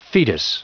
Prononciation du mot fetus en anglais (fichier audio)
Prononciation du mot : fetus